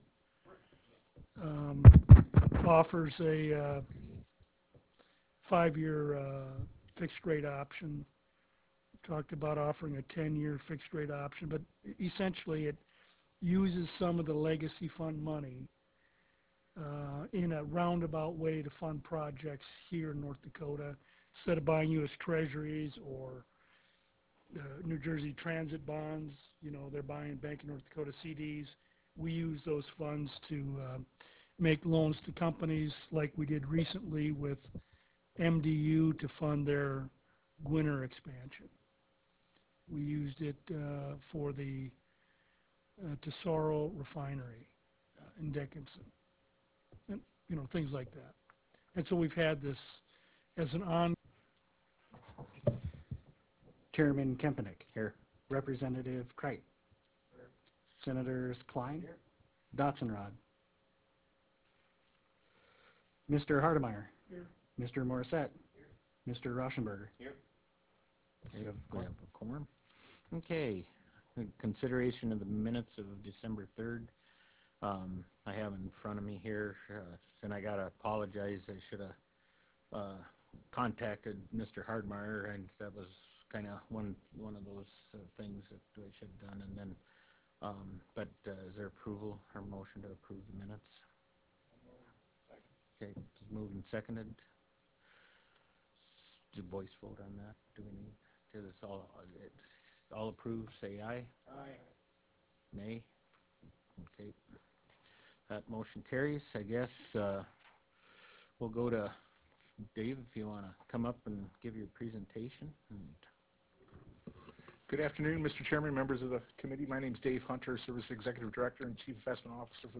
Medora Room State Capitol Bismarck, ND United States